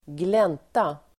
Uttal: [²gl'en:ta]